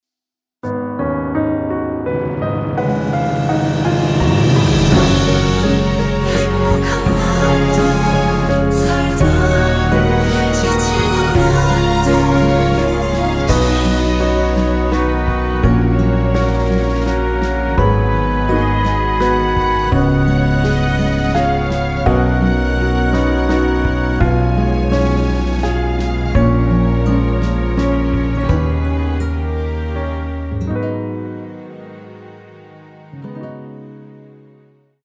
이곡의 코러스는 미리듣기에 나오는 부분이 전부 입니다.다른 부분에는 코러스가 없습니다.(미리듣기 확인)
원키에서(-2)내린 코러스 포함된 MR입니다.
F#
앞부분30초, 뒷부분30초씩 편집해서 올려 드리고 있습니다.
중간에 음이 끈어지고 다시 나오는 이유는